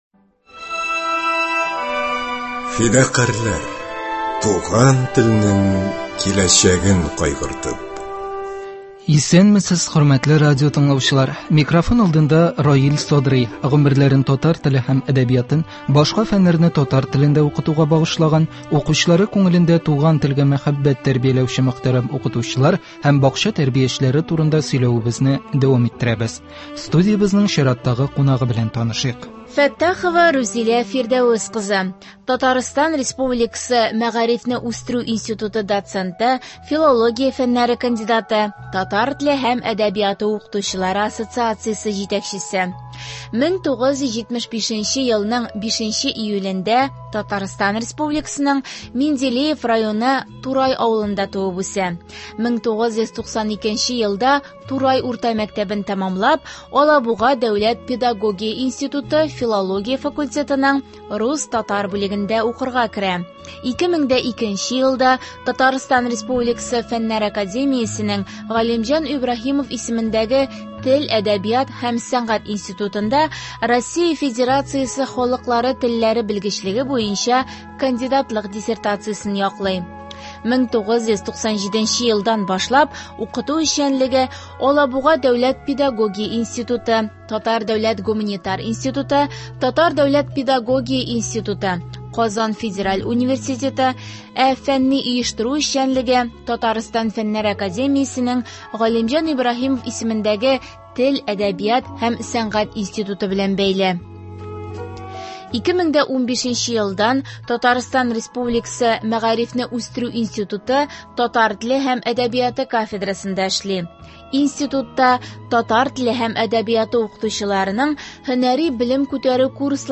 Гомерләрен татар теле һәм әдәбиятын, башка фәннәрне татар телендә укытуга багышлаган, укучылары күңелендә туган телгә мәхәббәт тәрбияләүче мөхтәрәм укытучылар һәм бакча тәрбиячеләре турында сөйләвебезне дәвам иттерәбез. Студиябезнең кунагы